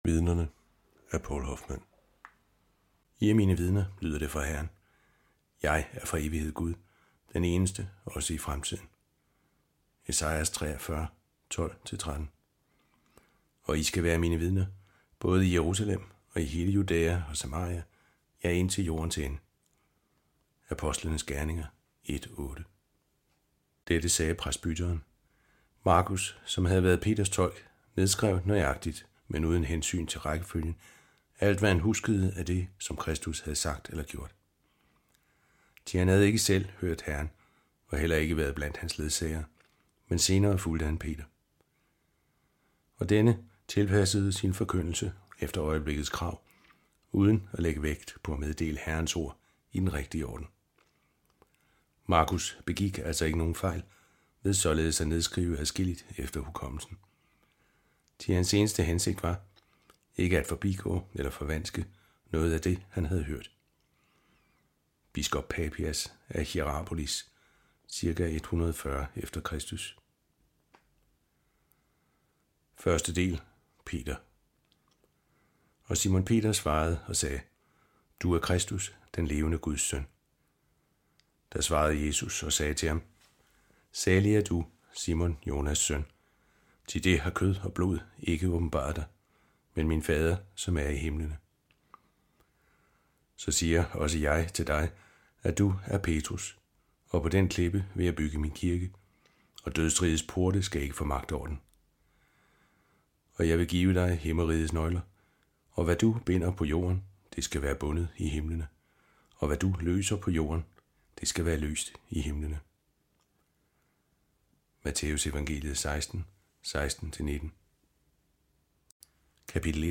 Hør et uddrag af Vidnerne Vidnerne Format MP3 Forfatter Poul Hoffmann Lydbog E-bog 149,95 kr.